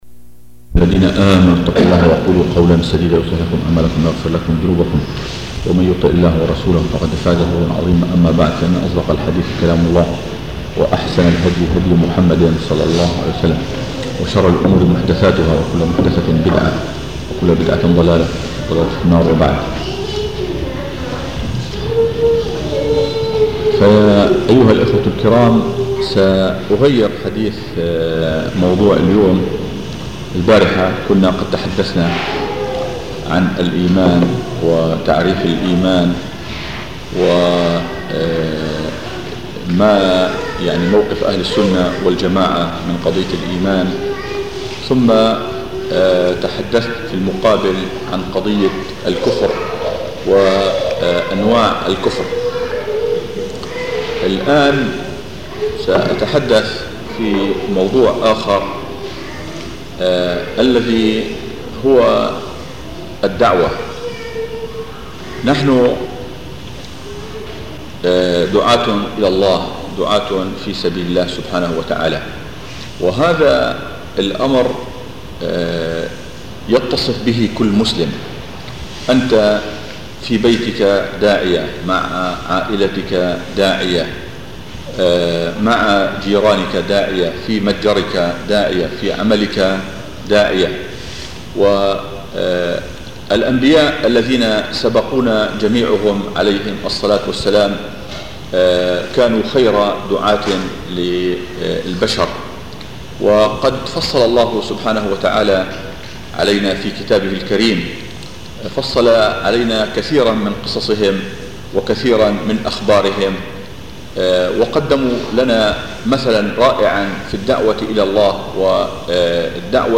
الدعوة-إلى-الله-مخيم-الإيمان.mp3